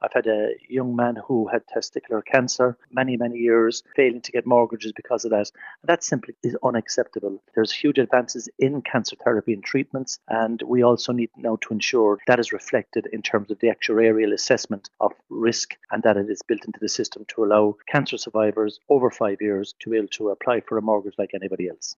MEP Billy Kelleher says some people are still being penalised years after disclosing a previous cancer diagnosis………….